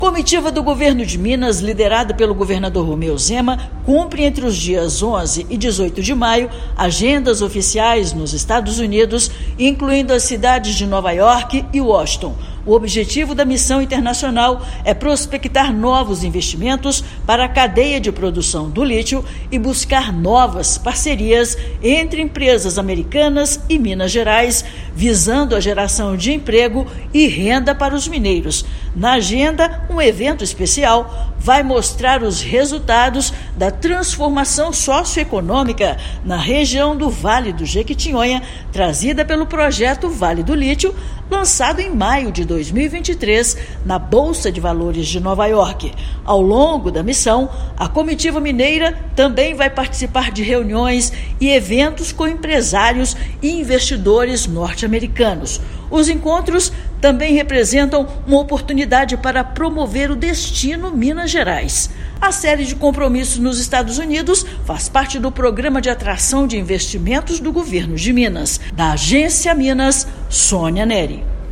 Dentre as agendas da comitiva chefiada pelo governador, destaque para reuniões com empresários e investidores e o detalhamento das ações para transformação socioeconômica da região do Jequitinhonha. Ouça matéria de rádio.